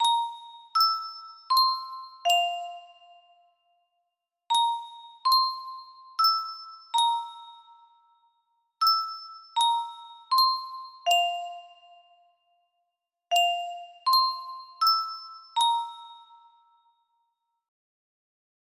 ... music box melody